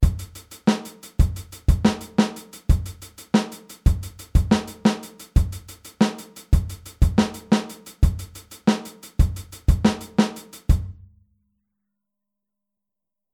Aufteilung linke und rechte Hand auf HiHat und Snare
Groove02b-16tel.mp3